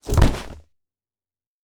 Foley Sports / Skateboard / Bail A.wav